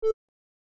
hover.mp3